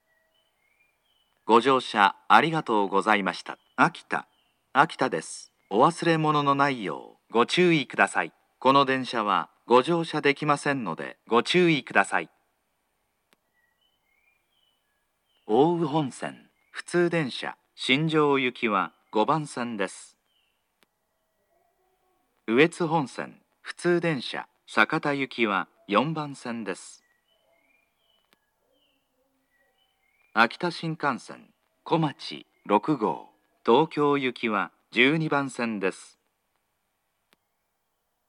akita-3p02_out-of-service.mp3